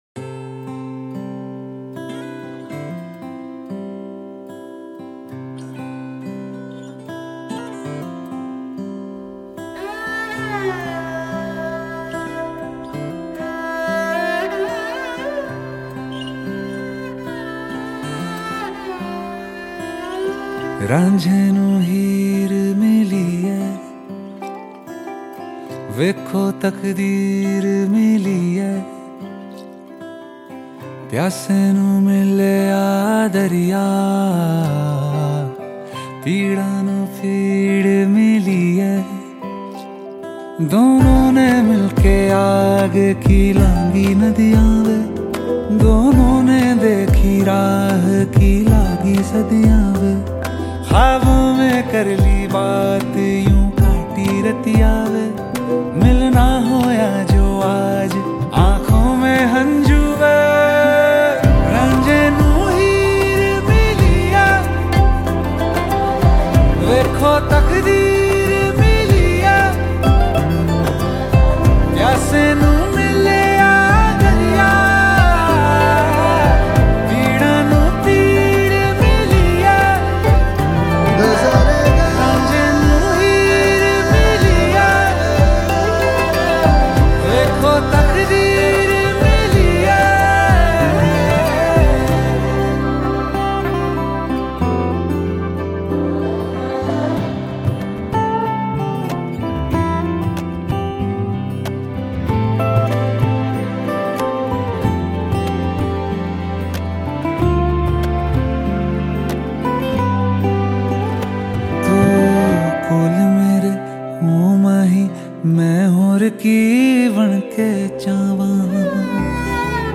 Bollywood